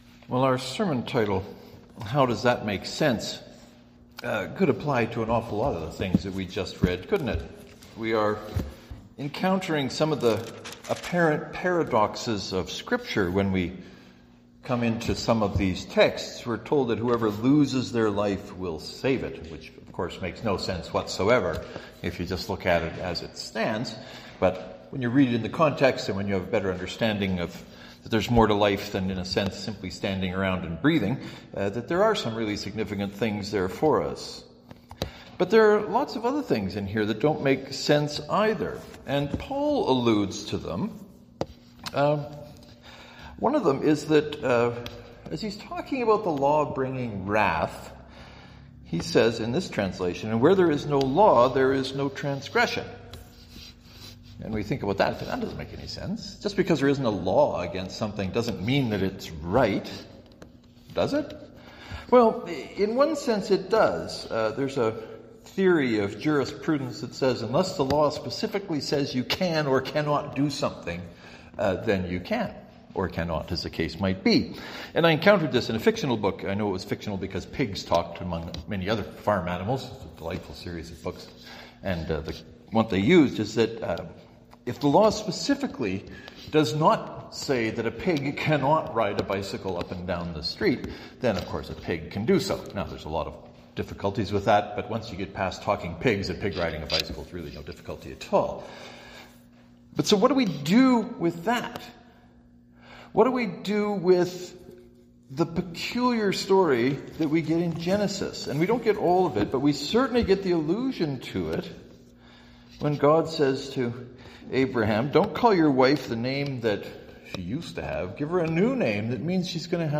I wrestled with the idea, and the sermon was the result.